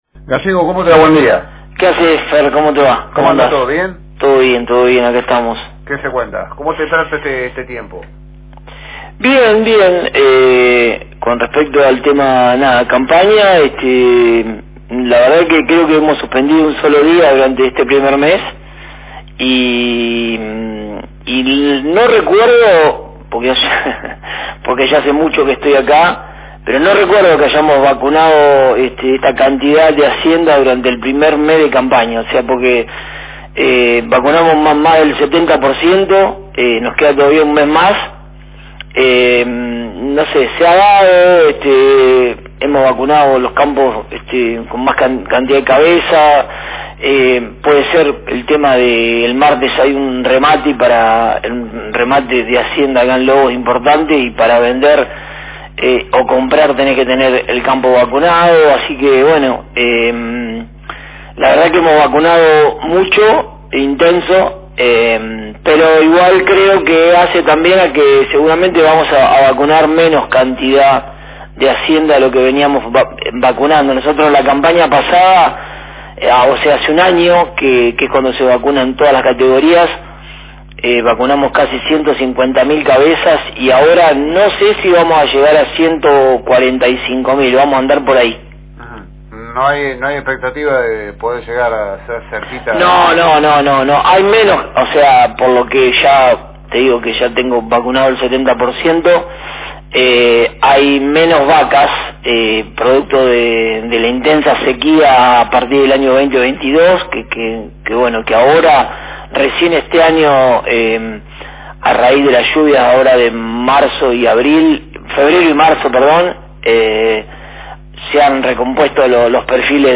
Durante la entrevista, se abordó también la situación actual del mercado ganadero.